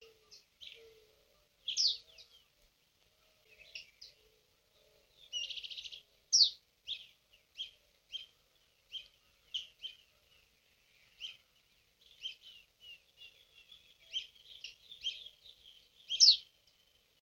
Southern Yellowthroat (Geothlypis velata)
Detailed location: Reserva privada Don Sebastián
Condition: Wild
Certainty: Observed, Recorded vocal